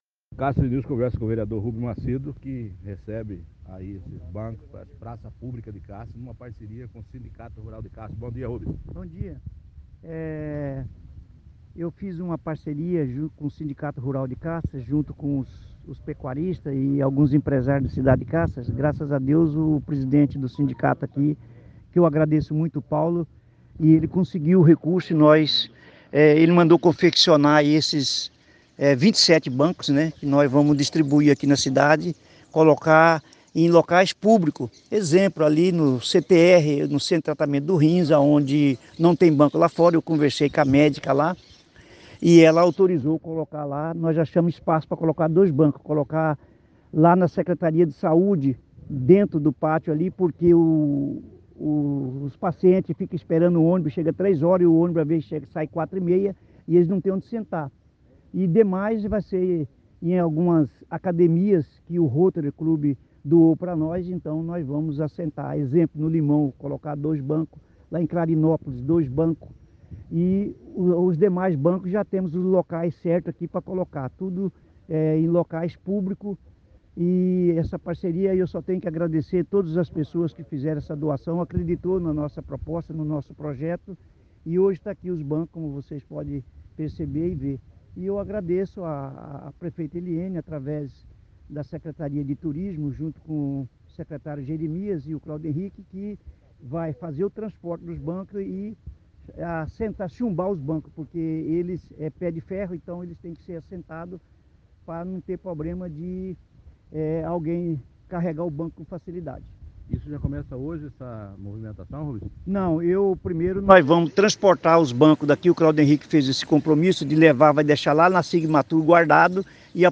Confira a fala do Vereador: